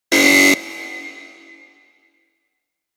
Jail Gate Buzzer Sound Effect
Short loud prison lock opening sound effect. Loud and realistic jail lock SFX, perfect for games, films, videos, and audio projects.
Jail-gate-buzzer-sound-effect.mp3